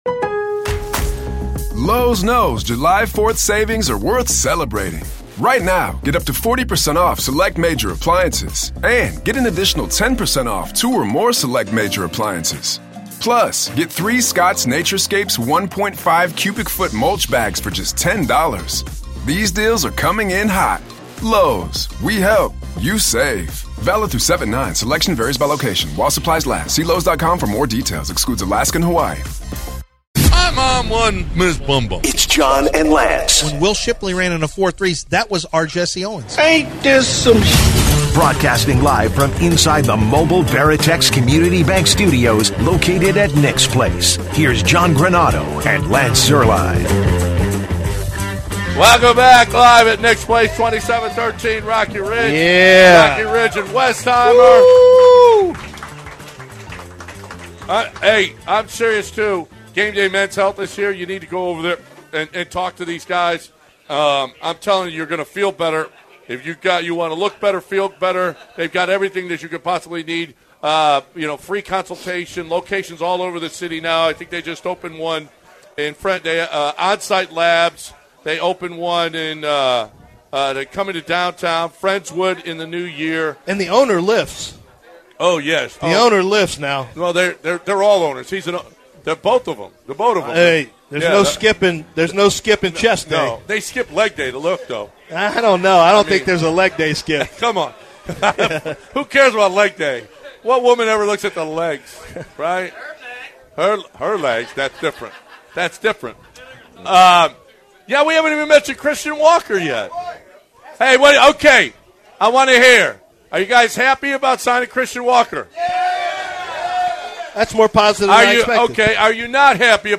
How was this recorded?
at the annual ESPN 97.5 Christmas Party at Nick's Place!